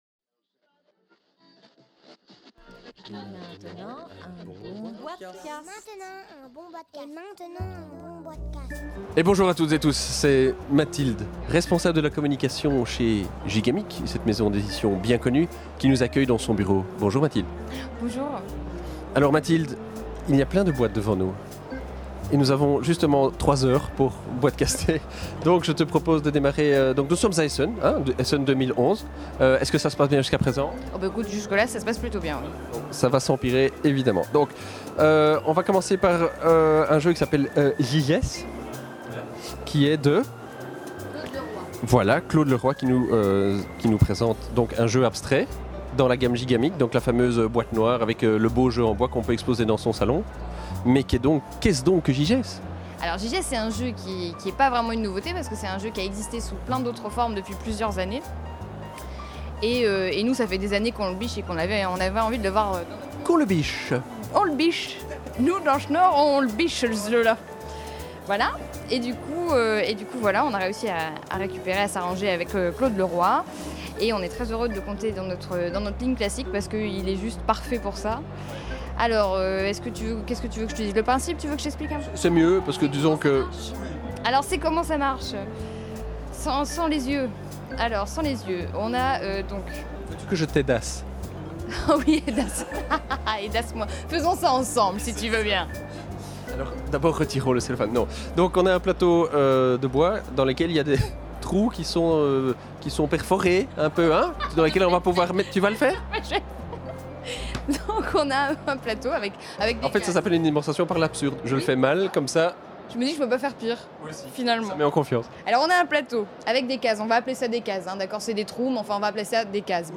(enregistré au Salon international du Jeu de Société de Essen – Octobre 2011)